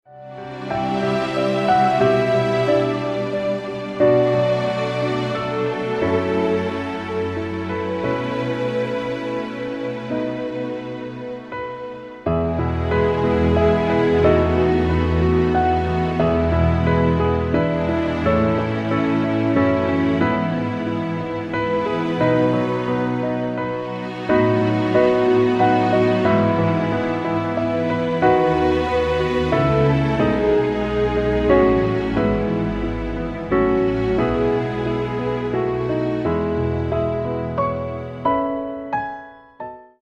Piano - Strings - Medium